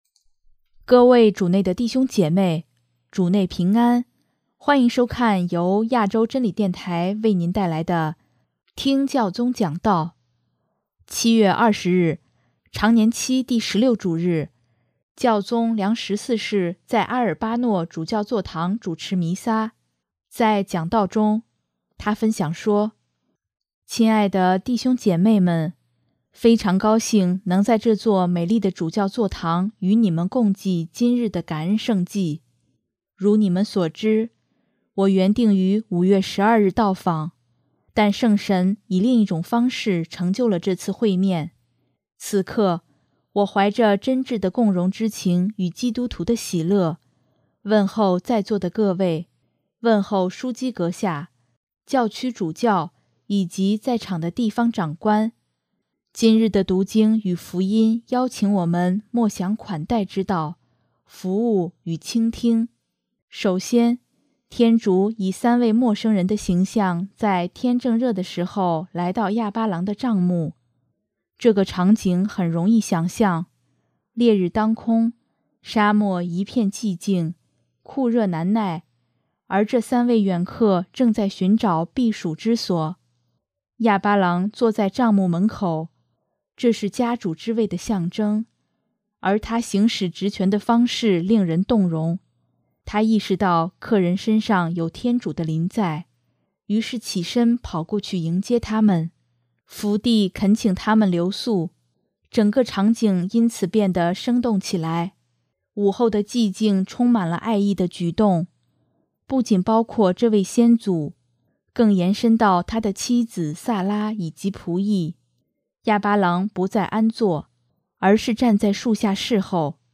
7月20日，常年期第十六主日，教宗良十四世在阿尔巴诺主教座堂主持弥撒，在讲道中，他分享说：